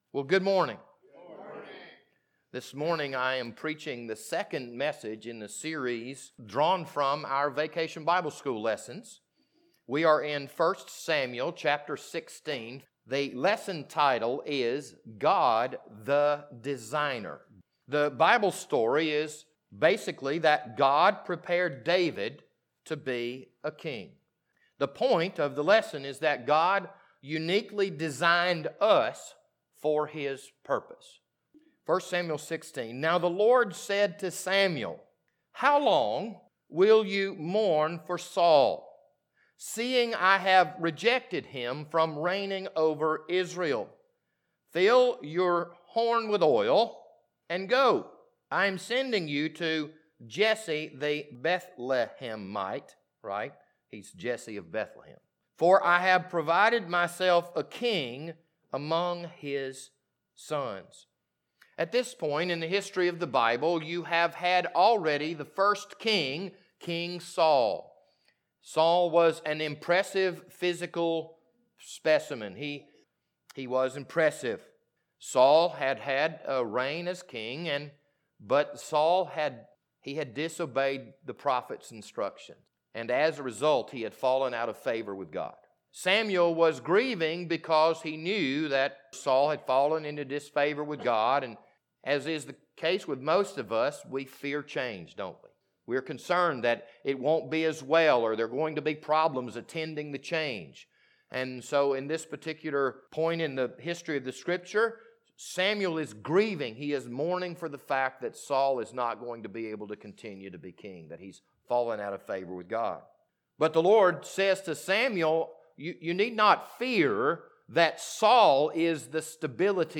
This Sunday morning sermon was recorded on April 24th, 2022.